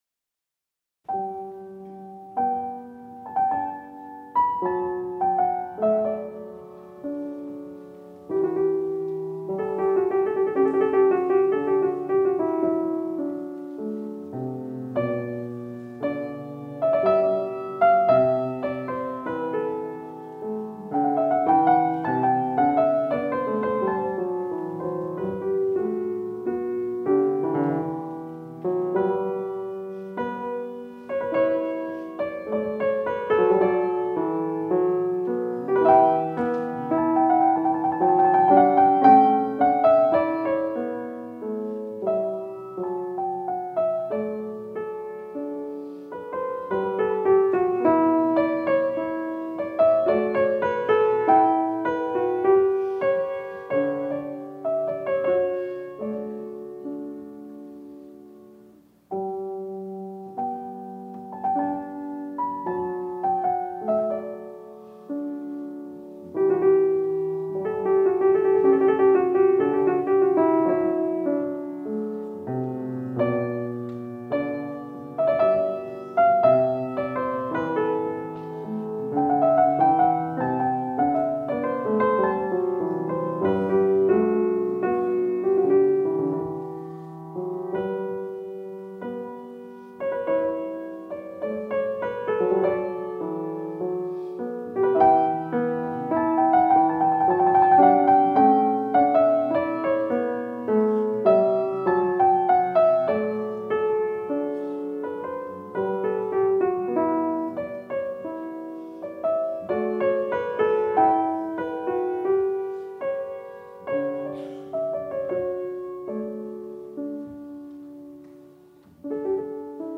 音樂類型：古典音樂
Recorded in Marlboro on July 3, 1976